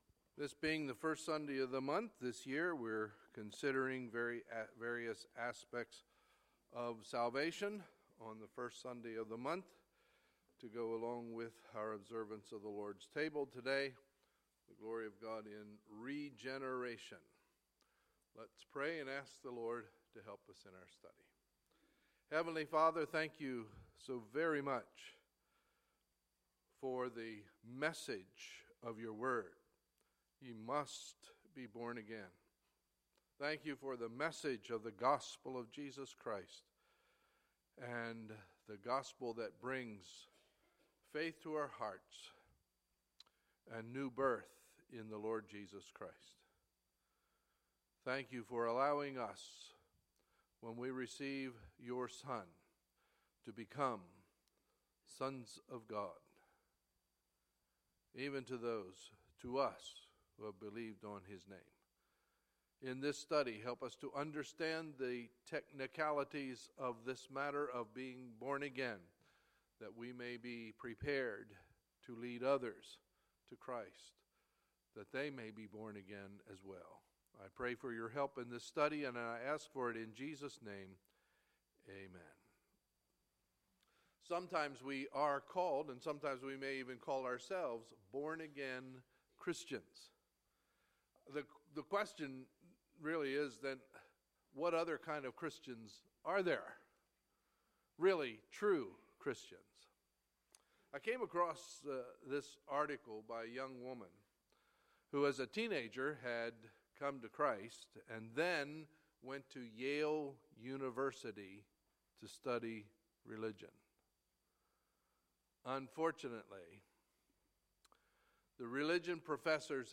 Sunday, October 2, 2016 – Sunday Morning Service